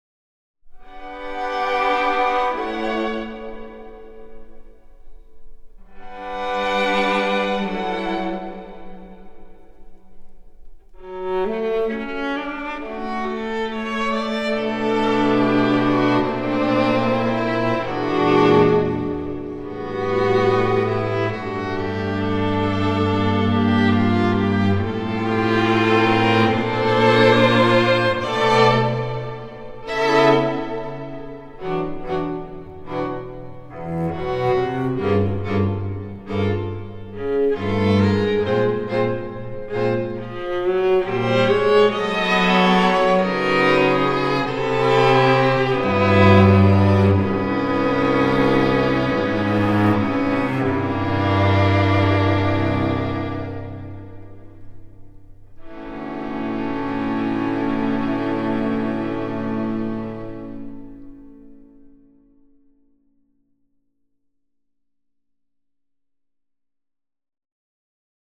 弦楽四重奏